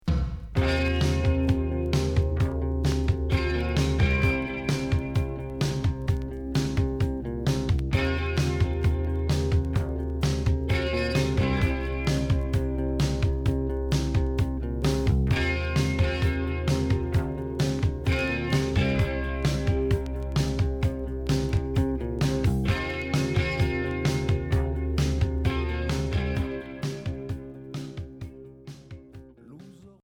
Rock new wave Premier 45t retour à l'accueil